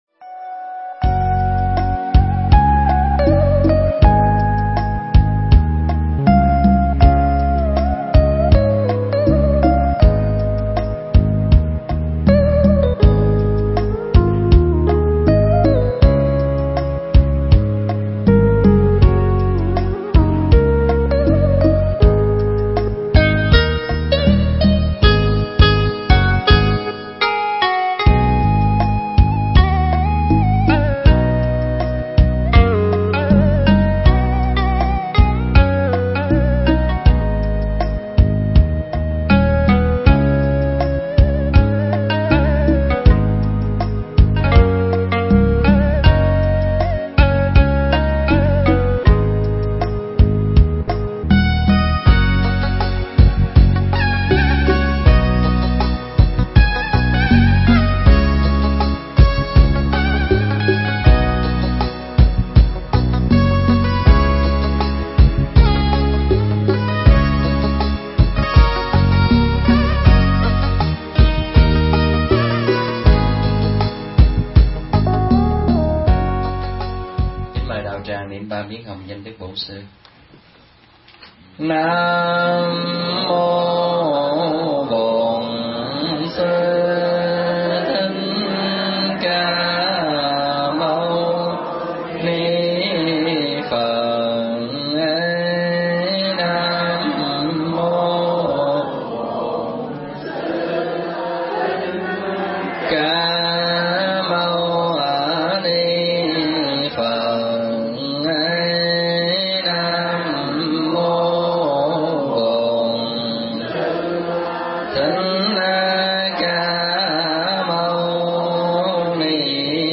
Mp3 Pháp Thoại Sống Tốt Để Lòng Thanh Thản